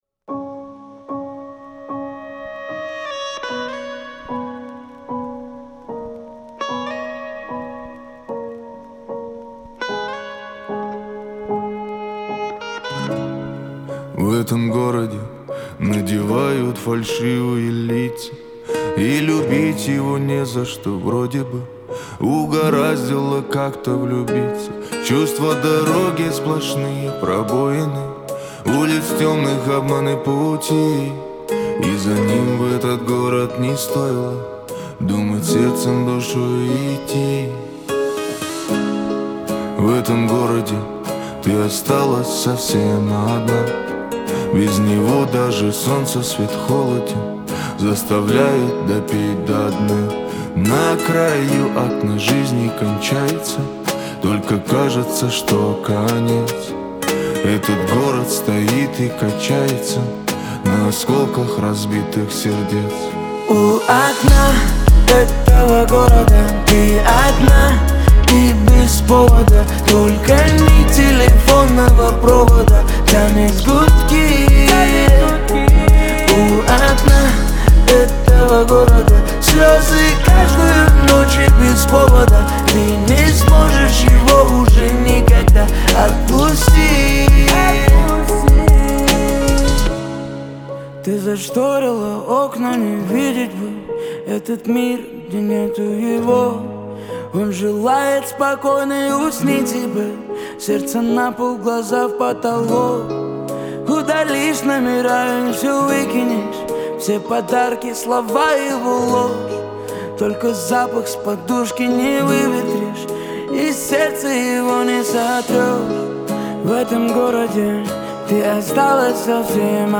это трек в жанре рэп и R&B